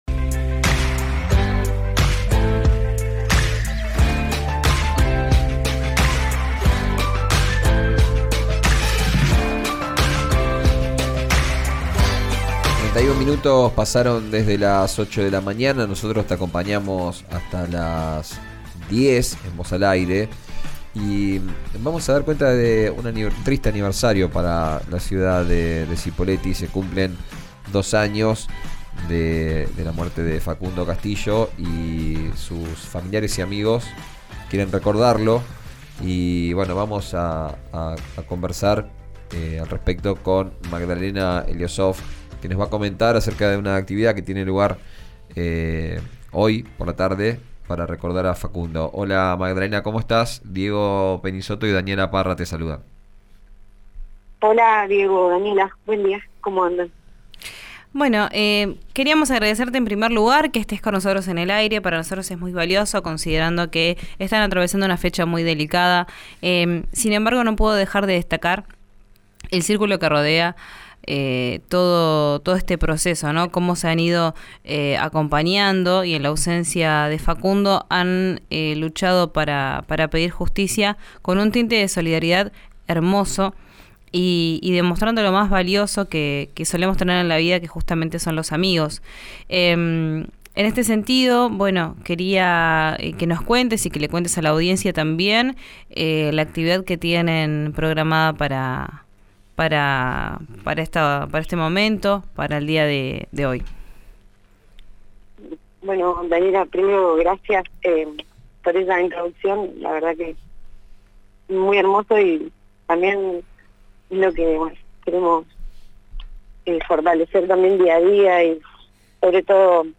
En diálogo con RÍO NEGRO RADIO adelantaron cómo será la reunión.